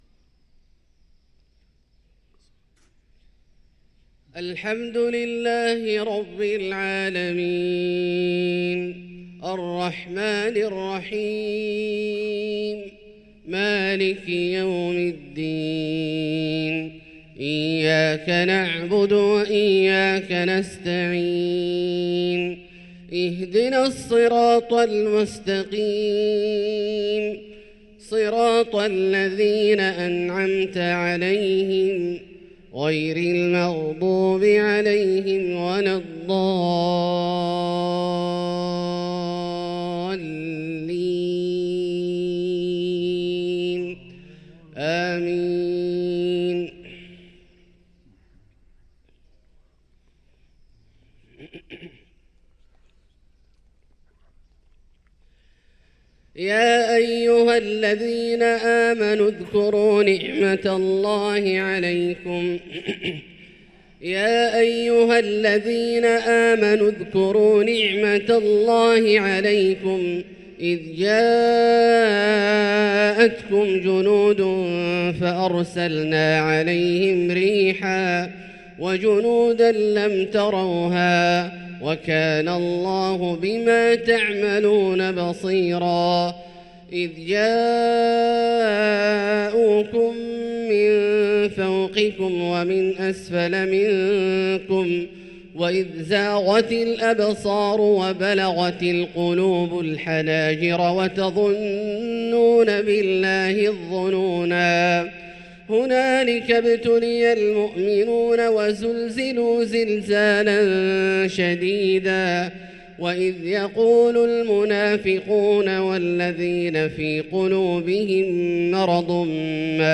صلاة الفجر للقارئ عبدالله البعيجان 8 جمادي الأول 1445 هـ
تِلَاوَات الْحَرَمَيْن .